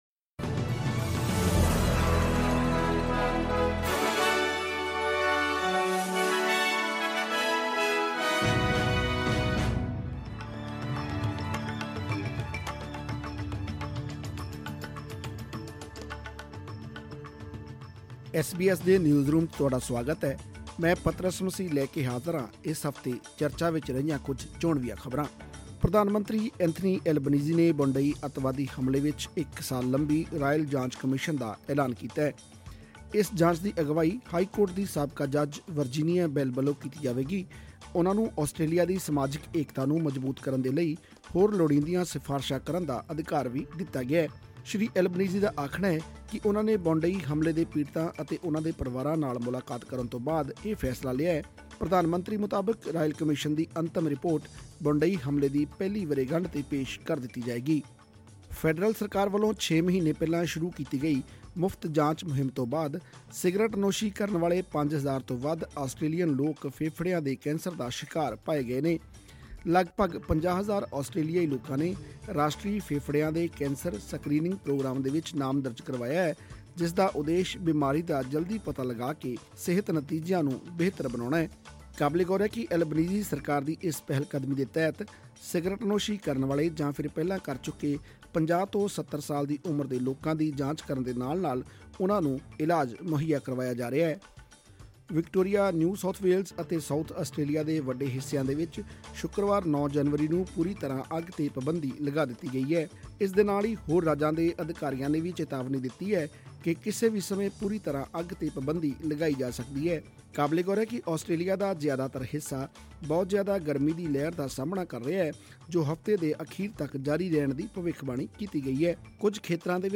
Top news updates of the week in Punjabi.